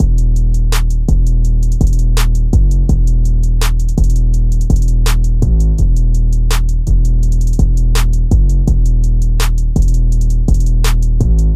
描述：带有低通的Arp合成器
标签： 166 bpm Hip Hop Loops Synth Loops 1.95 MB wav Key : Unknown
声道立体声